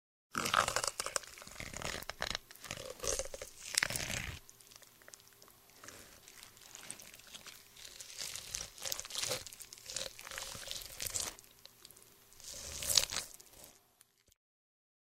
Звуки ужаса
На этой странице собраны самые жуткие звуки ужаса: скрипы, стоны, шаги в темноте, леденящие душу крики и другие пугающие эффекты.
Жуют кость человека